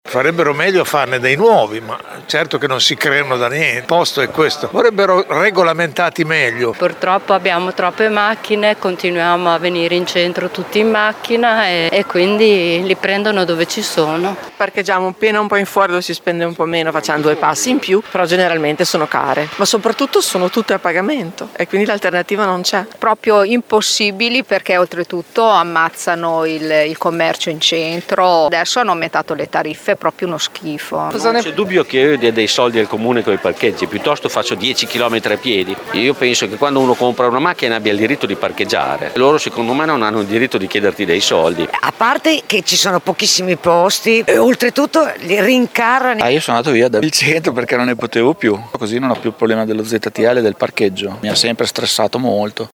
VOX-AUMENTI-PARCHEGGI.mp3